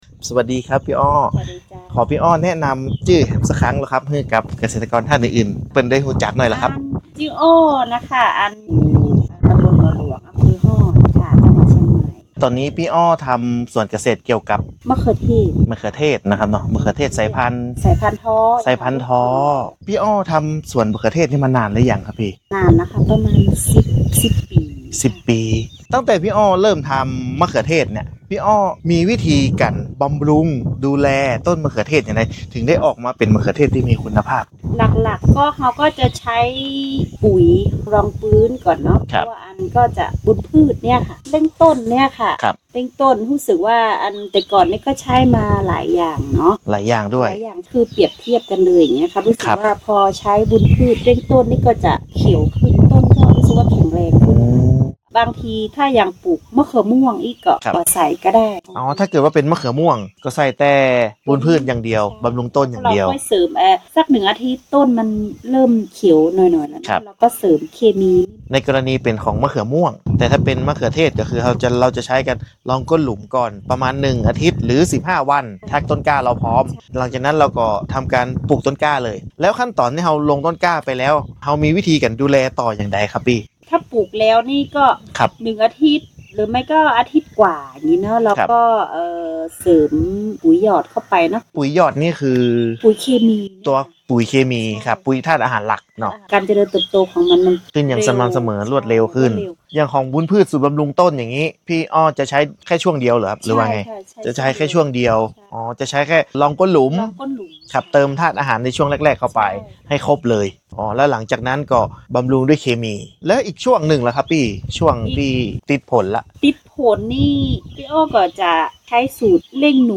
เกษตรกร